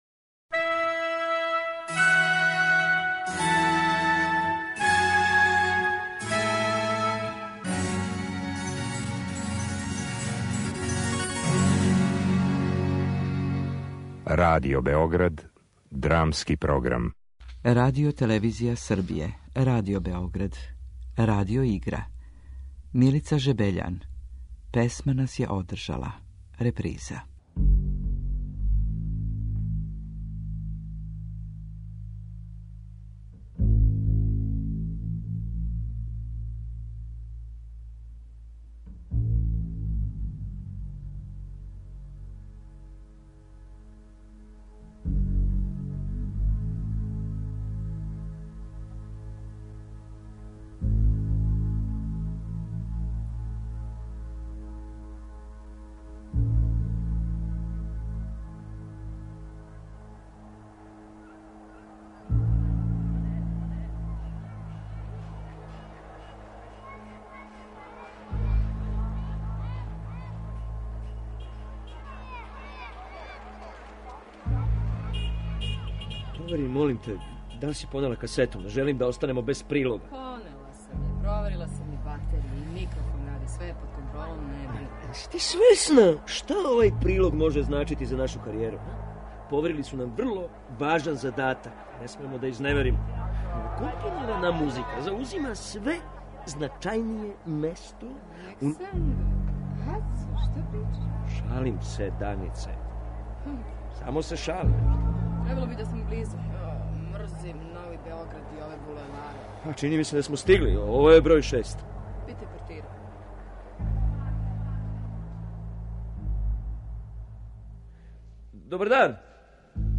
Драмски програм: Радио-игра
Радио-игра